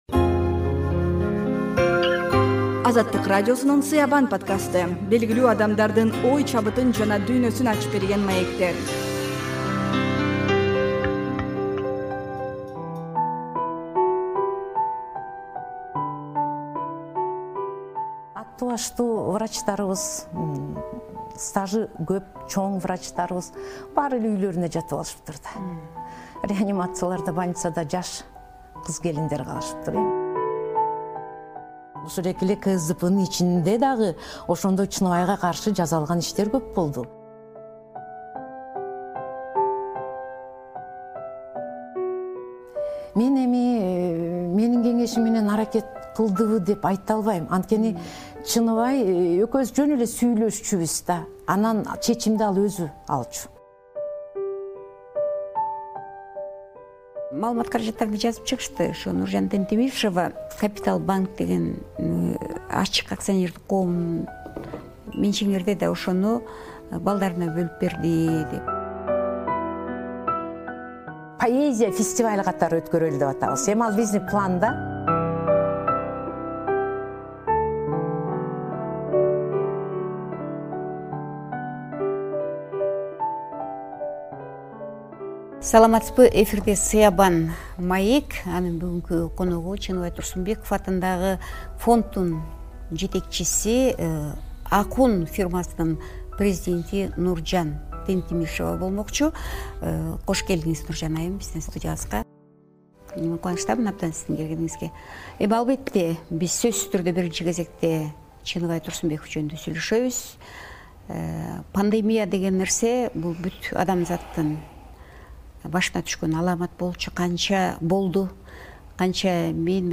сыябан-маек курду.